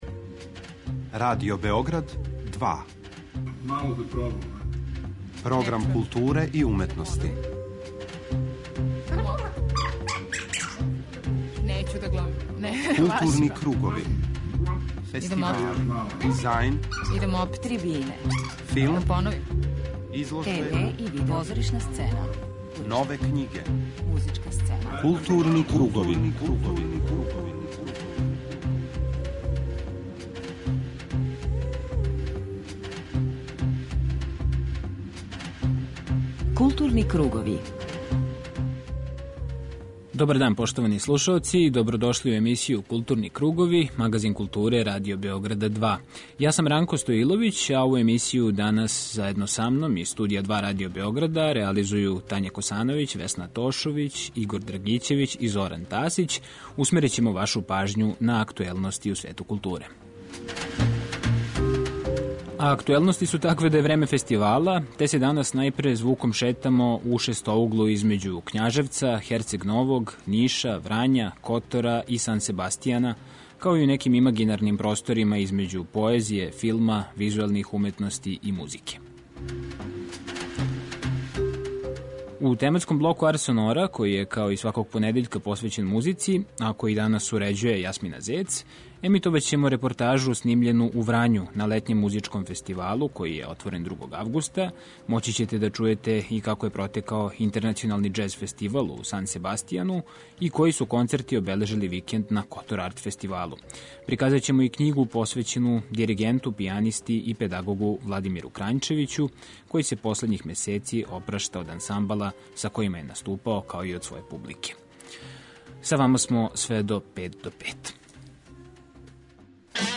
У тематском блоку Арс сонора емитоваћемо репортажу снимљену у Врању на летњем музичком фестивалу који је отворен 2. августа.